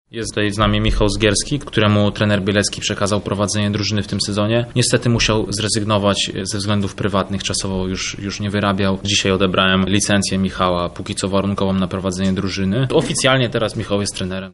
… tłumaczył na konferencji prasowej menedżer zespołu